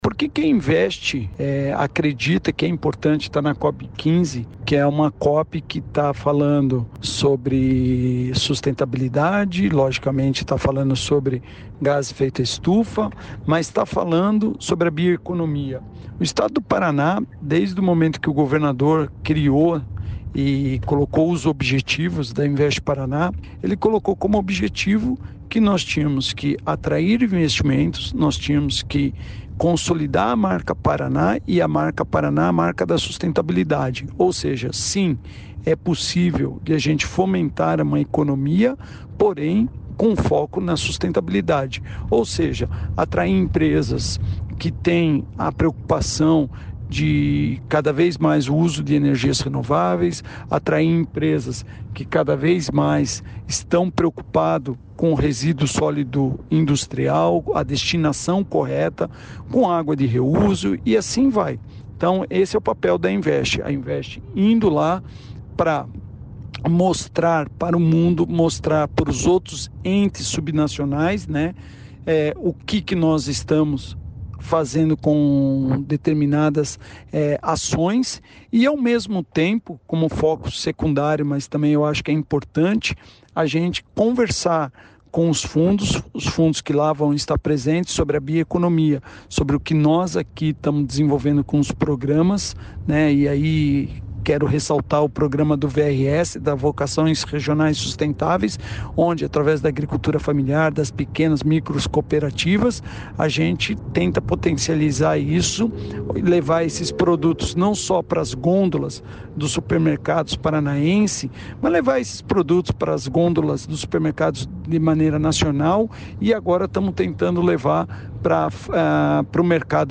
Sonora do diretor-presidente da Invest Paraná, Eduardo Bekin, sobre a participação do Paraná na COP15